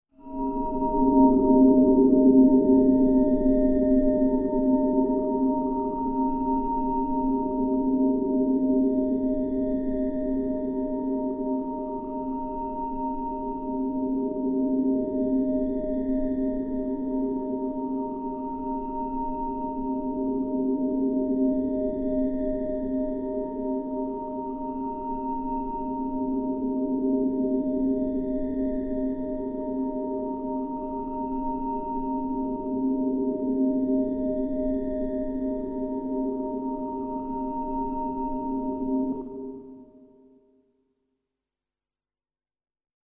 Efecto especial de voces en sueños 01